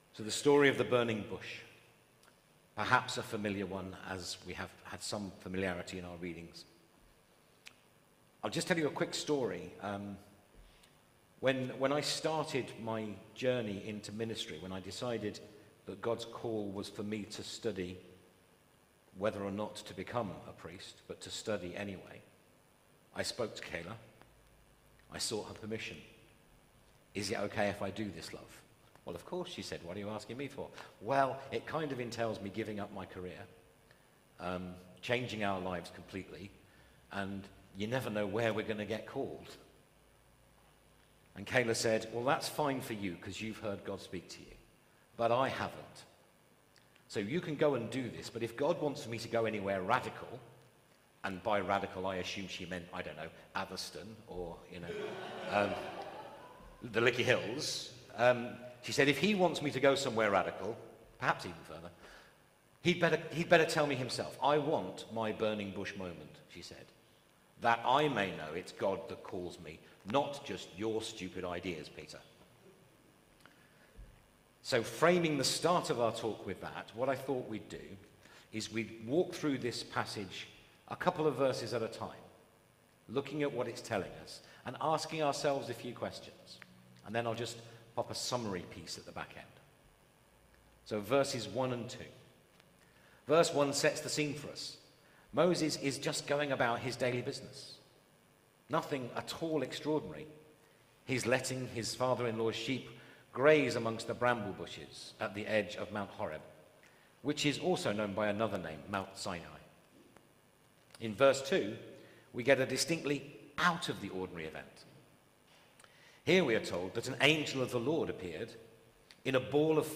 Media for Midweek Communion on Wed 16th Jul 2025 10:00 Speaker
Theme: God calls Sermon Search